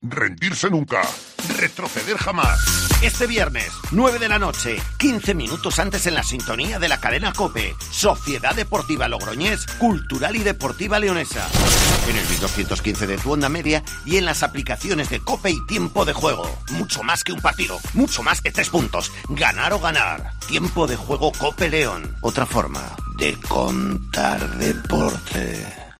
Escucha la cuña promocional del partido Logroñes- Cultural el día 12-11-21 a las 21:00 h en el 1.215 OM